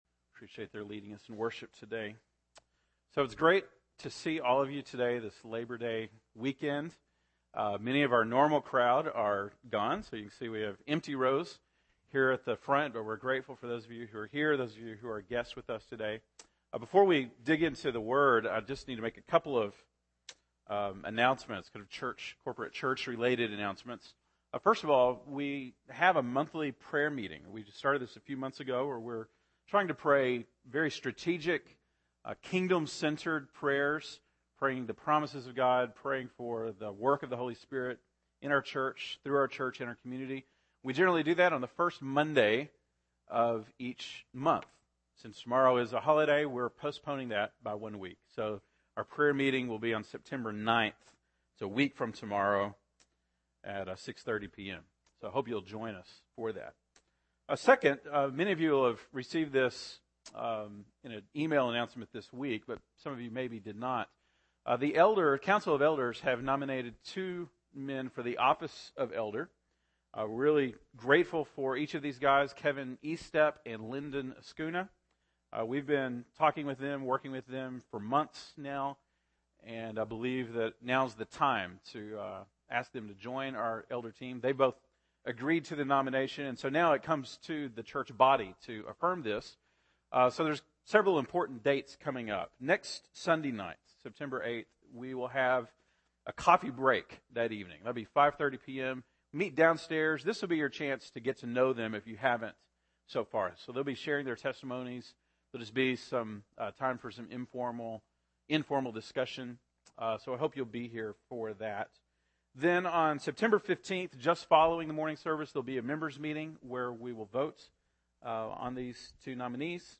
September 8, 2013 (Sunday Morning)